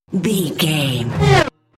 Riser electronic fast
Sound Effects
In-crescendo
Atonal
Fast
bouncy
bright
driving
futuristic
intense